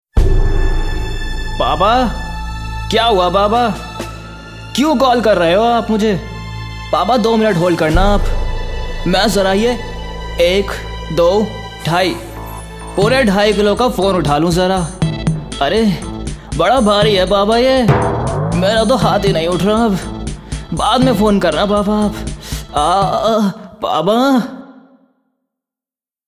Medium Base, Smooth, Pleasant, Soft &#61558
My Dialect is Hindi-Urdu.
Sprechprobe: Sonstiges (Muttersprache):